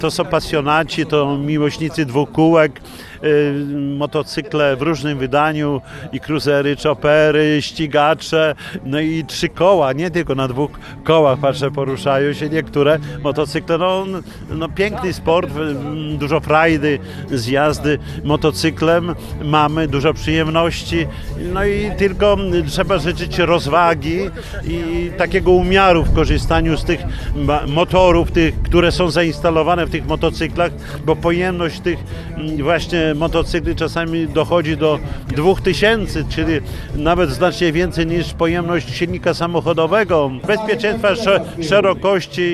Udanego sezonu życzył motocyklistom Czesław Renkiewicz, prezydent Suwałk.